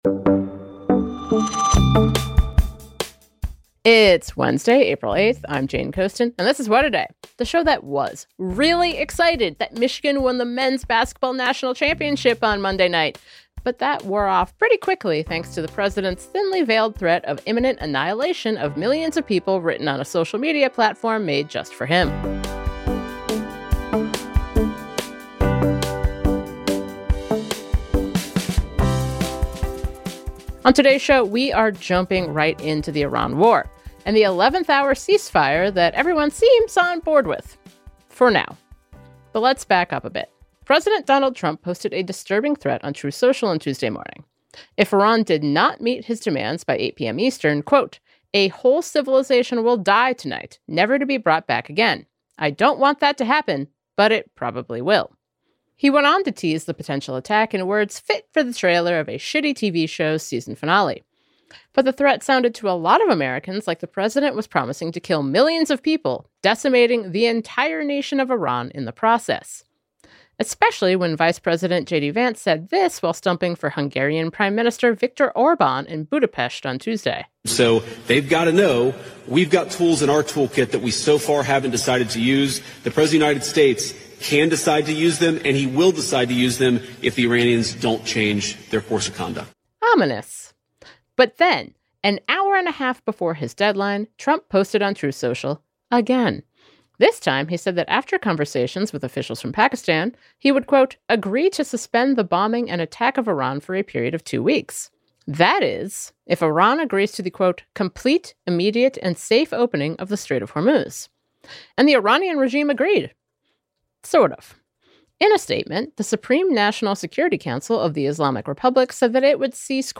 We spoke with Arizona Democratic Representative Yassamin Ansari shortly after the news broke that U.S. and Iranian officials had agreed to a ceasefire.